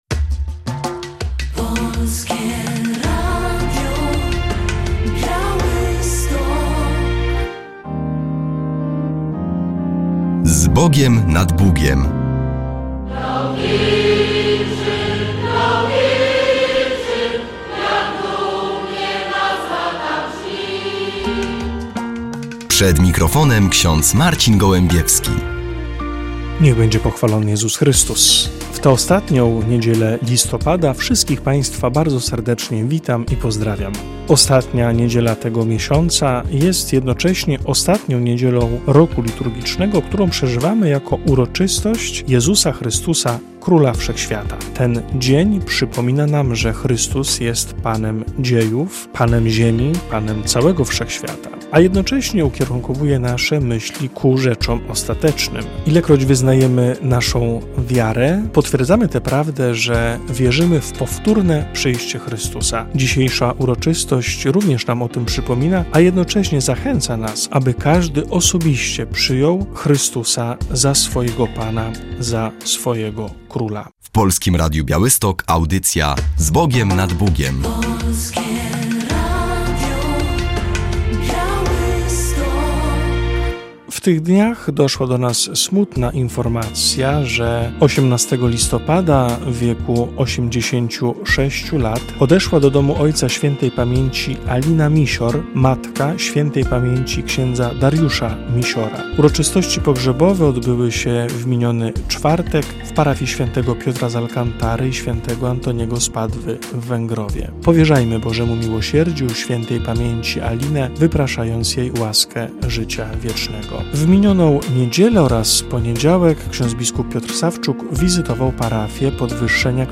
W audycji relacja z uroczystości 600-lecia miejscowości Sterdyń.